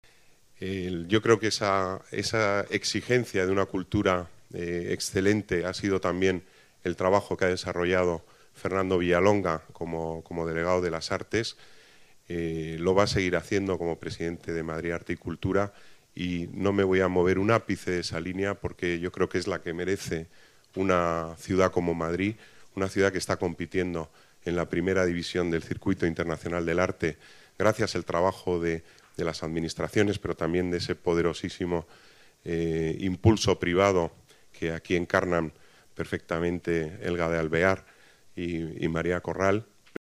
Nueva ventana:El delegado Pedro Corral destaca la calidad de esta exposición en CentroCentro